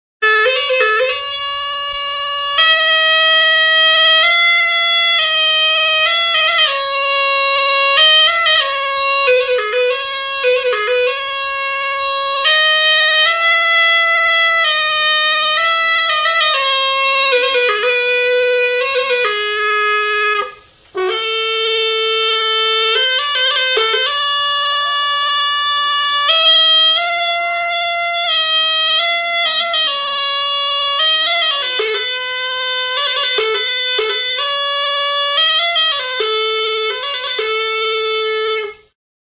Aerófonos -> Lengüetas -> Simple (clarinete)
EUROPA -> ANDALUZIA
GAITA GASTOREÑA
Klarinete sinplea da.
Fita airean du, eta hotsa handitzeko adar handi bat du beheko muturrean.